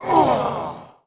client / bin / pack / Sound / sound / monster / fox_ninetail / dead_1.wav
dead_1.wav